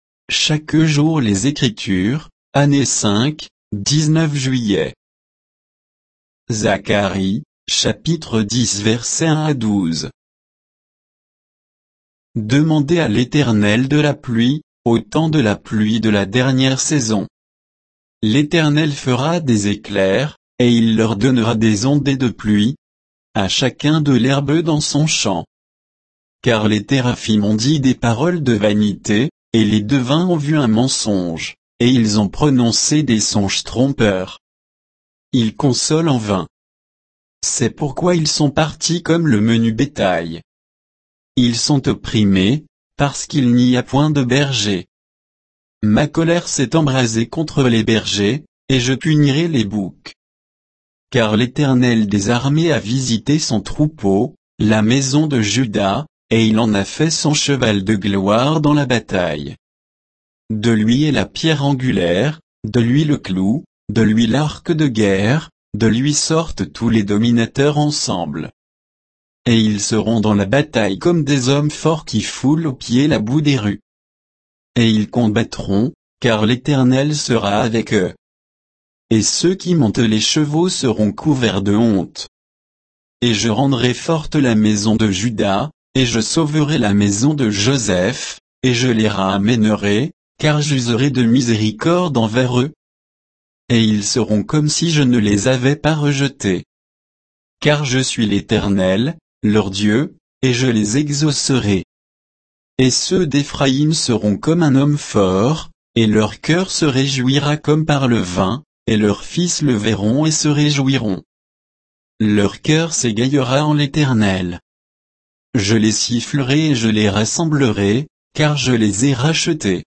Méditation quoditienne de Chaque jour les Écritures sur Zacharie 10